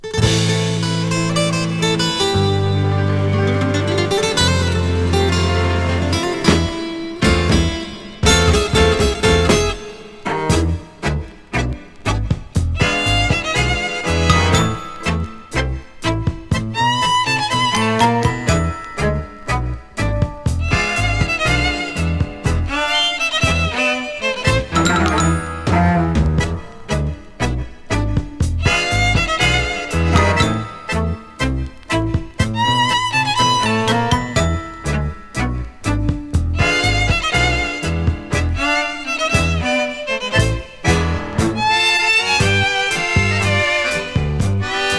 用途に応じて様々な音仕掛け。タンゴ有り、カントリー有り、秘境有り、ほんわか有り?!と、次から次へと聴き手を刺激。